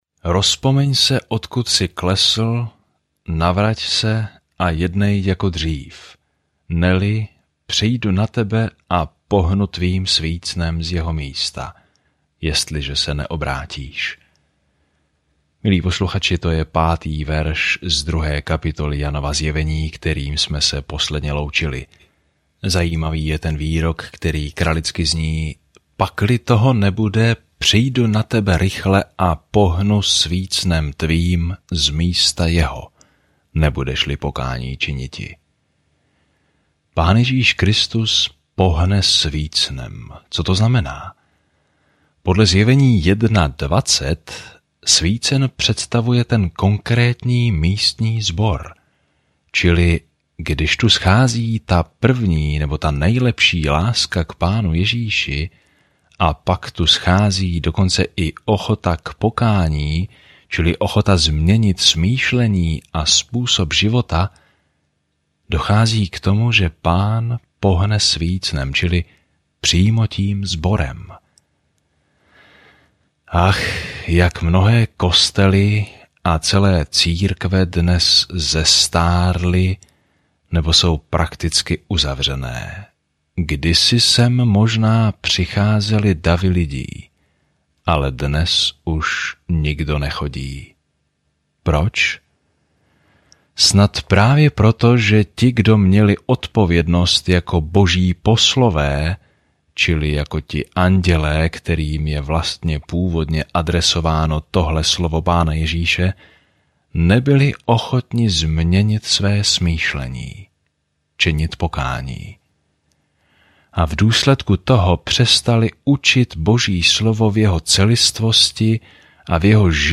Písmo Zjevení 2:6-11 Den 8 Začít tento plán Den 10 O tomto plánu Zjevení zaznamenává konec rozsáhlé časové osy dějin s obrazem toho, jak bude se zlem konečně zacházeno a Pán Ježíš Kristus bude vládnout ve vší autoritě, moci, kráse a slávě. Denně procházejte Zjevení a poslouchejte audiostudii a čtěte vybrané verše z Božího slova.